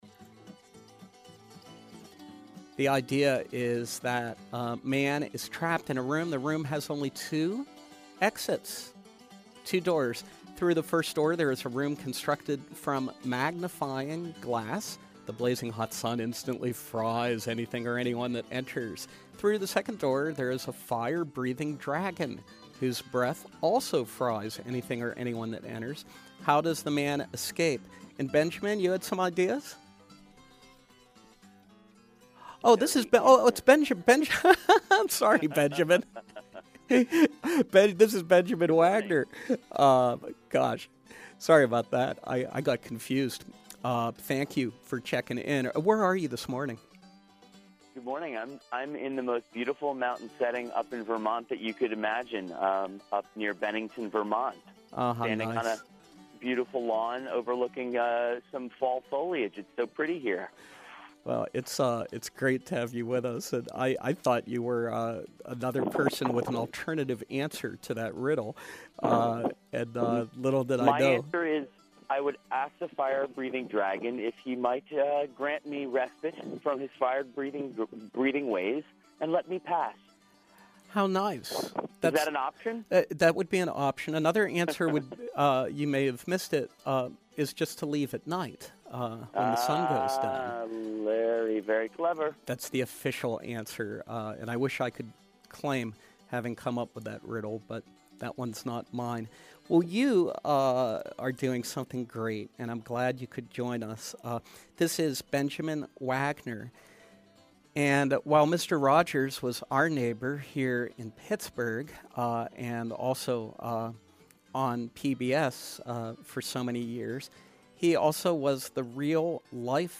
The Making of Mister Rogers & Me: Interview